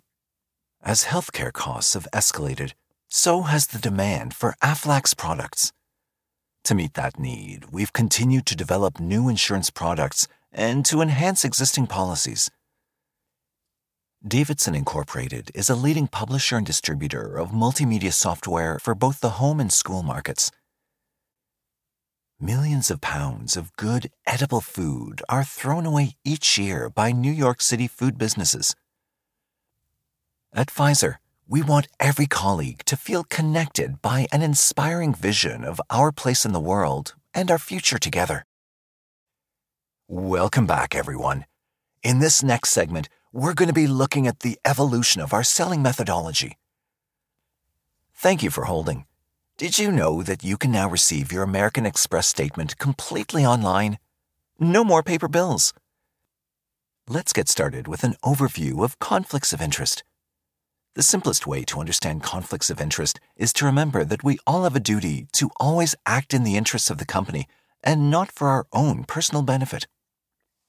Corporate demo
Known for my warm, resonant baritone, I provide clear, trusted narration for commercials, web videos, eLearning, corporate content, explainers, IVR, animated characters, video games, and more.